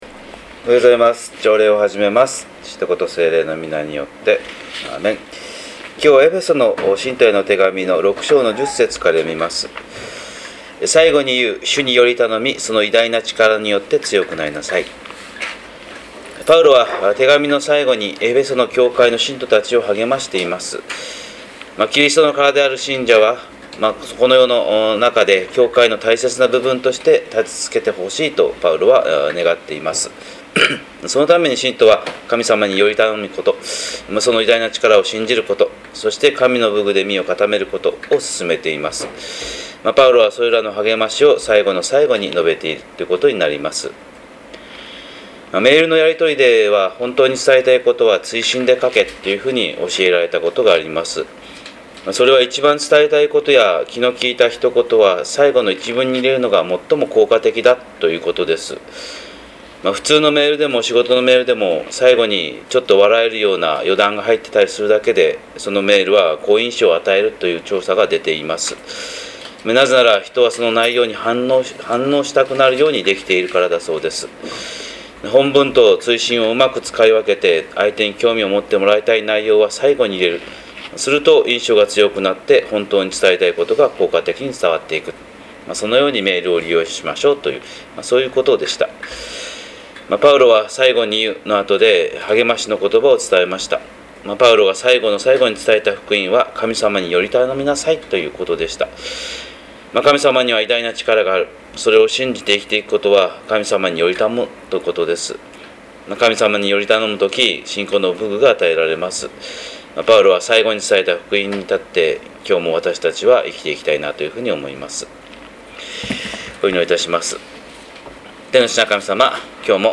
神様の色鉛筆（音声説教）
日本福音ルーテル教会（キリスト教ルター派）牧師の朝礼拝説教です！